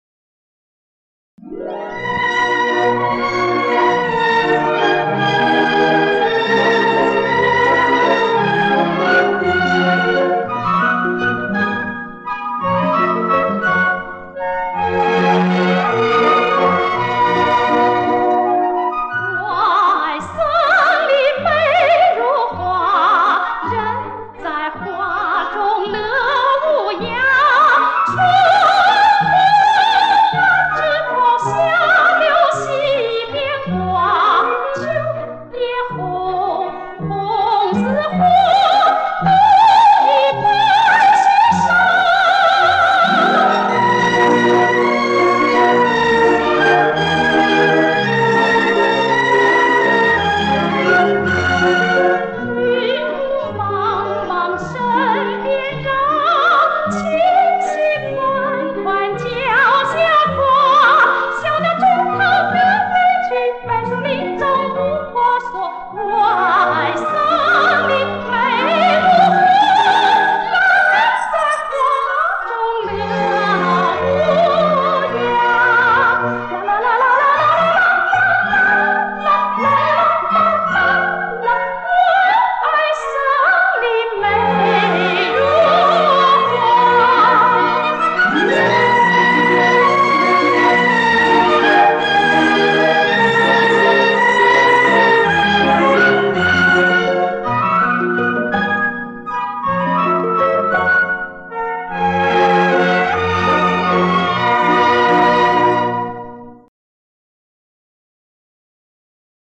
三拍子，出现在影片中的具体场景现在弄不清楚了。